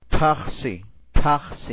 Tabla I: Alfabeto Oficial sonorizado
Oclusivas aspiradas ph